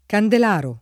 Candelaro [ kandel # ro ] top. m. (Puglia)